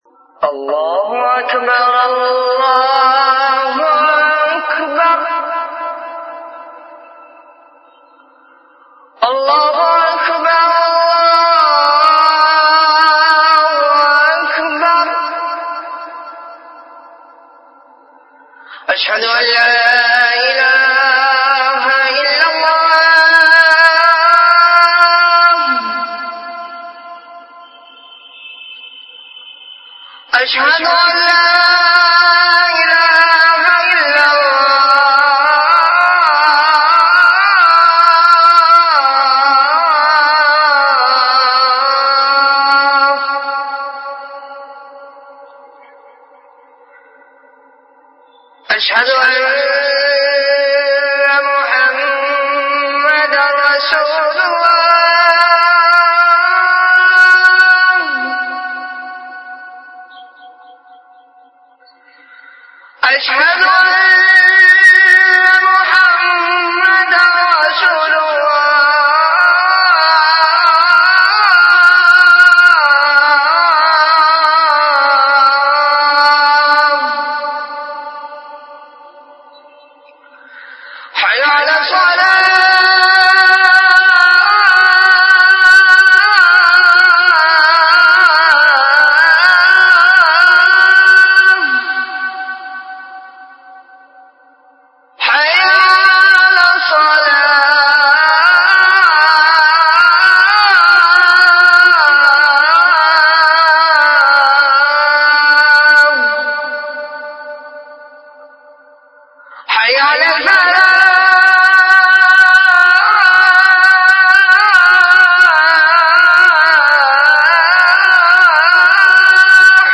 Azan · Jamia Masjid Bait-ul-Mukkaram, Karachi
CategoryAzan
VenueJamia Masjid Bait-ul-Mukkaram, Karachi
Event / TimeAfter Isha Prayer